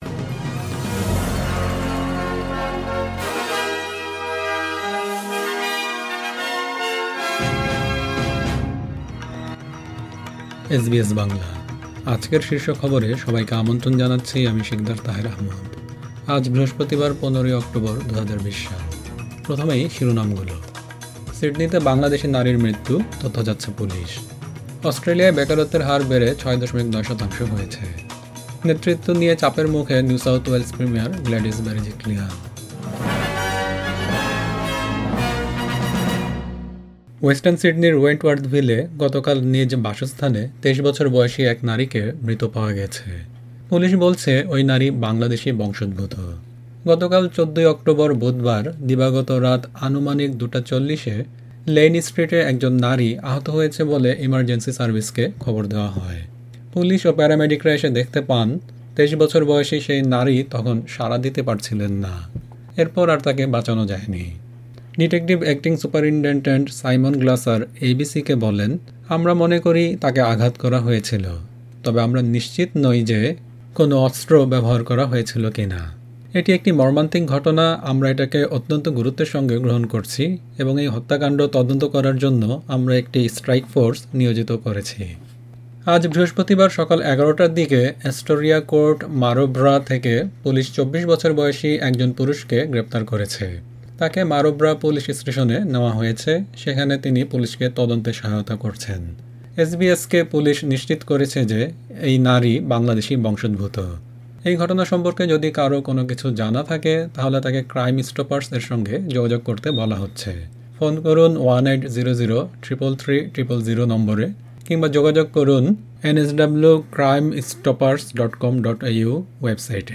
এসবিএস বাংলা শীর্ষ খবর: ১৫ অক্টোবর ২০২০